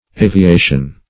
Aviation \A`vi*a"tion\, n.